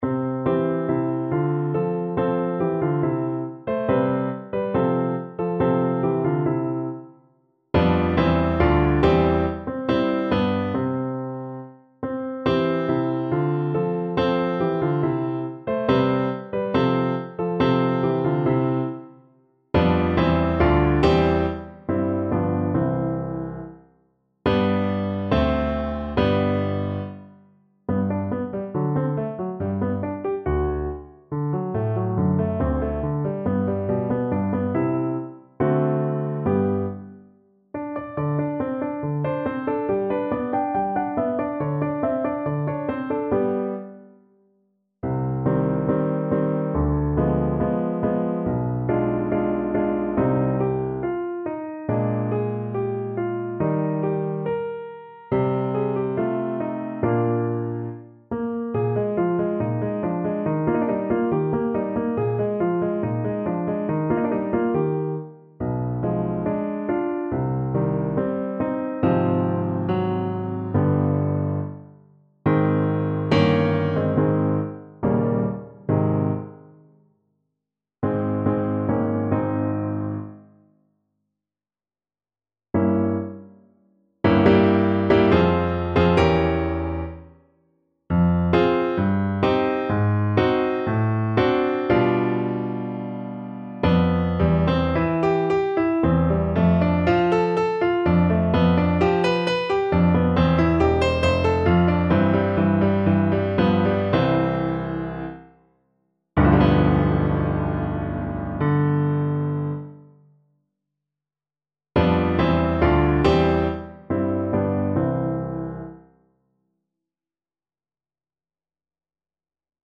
C major (Sounding Pitch) (View more C major Music for Contralto Voice )
= 70 Allegretto
Classical (View more Classical Contralto Voice Music)